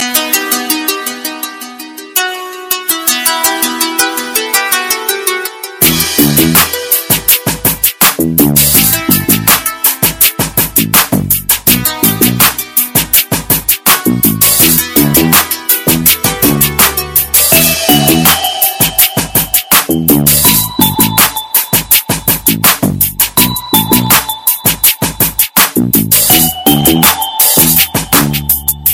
электронная музыка
Танцевальные рингтоны , громкие рингтоны , Рингтоны без слов
атмосферные
Электронные